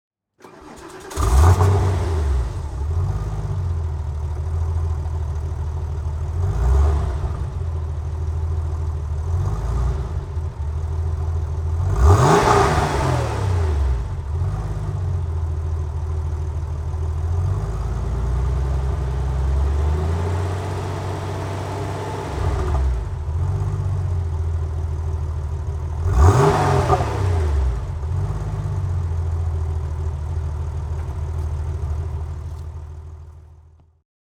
Motorsounds und Tonaufnahmen zu Porsche Fahrzeugen (zufällige Auswahl)
Porsche 993 Cabrio (1995) - Starten und Leerlauf